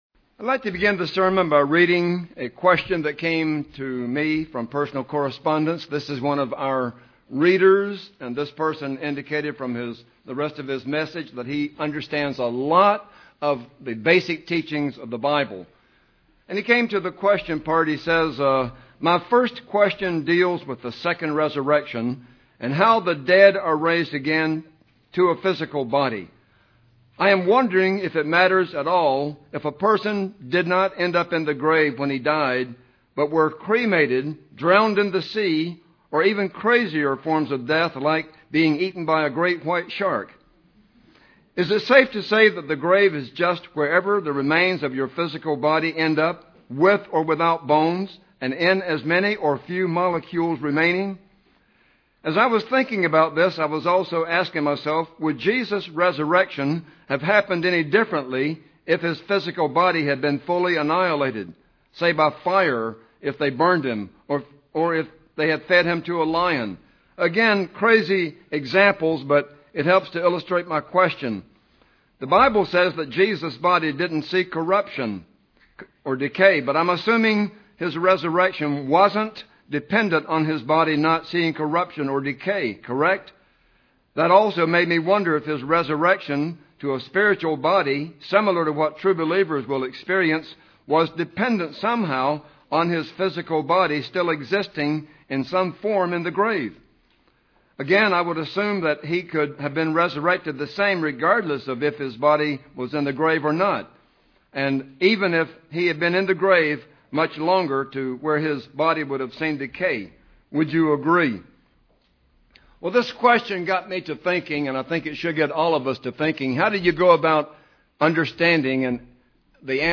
Listen to this sermon to find out what God says about the spirit in man.